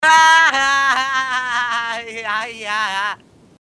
risada_5.mp3